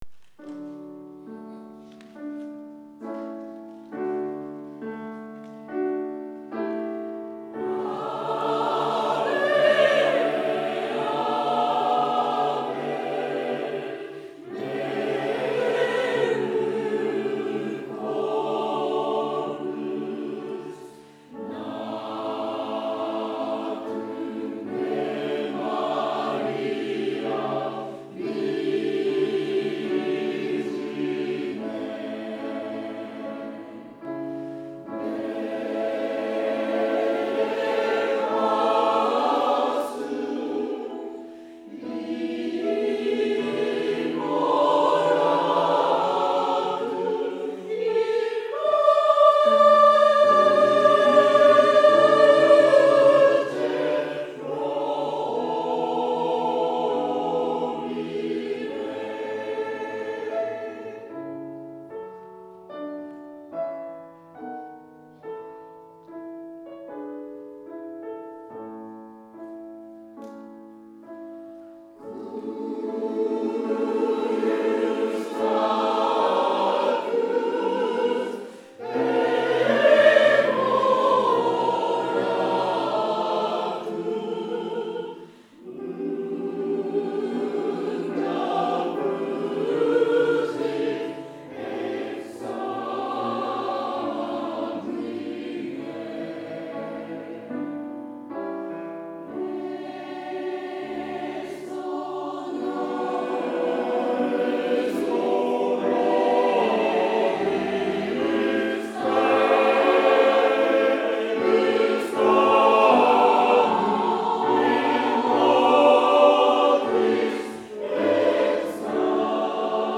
choir.wav